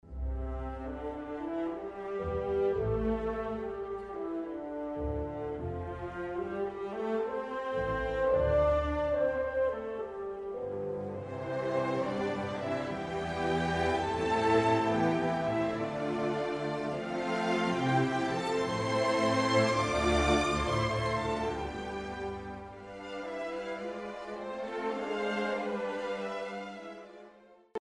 but in the middle section, Coleridge-Taylor introduces a spiritual-like melody in the unusual time signature of 5/4.